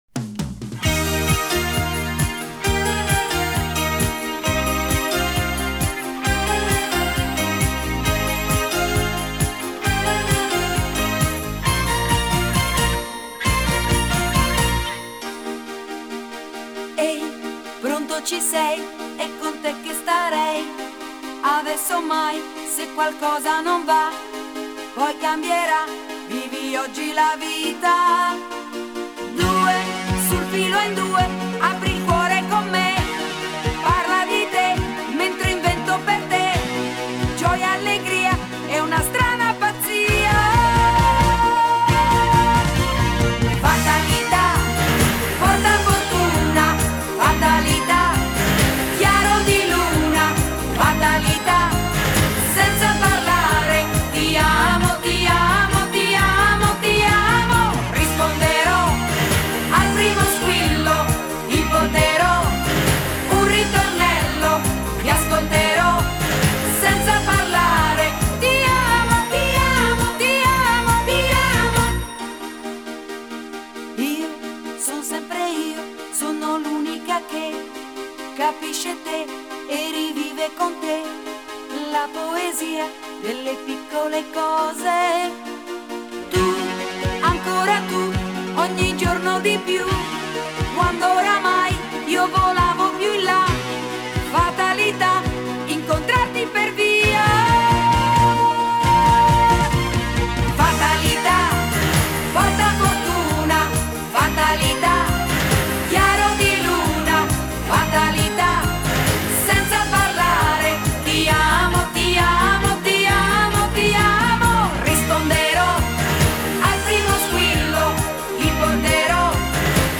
Жанр: Ретро, размер 6.94 Mb.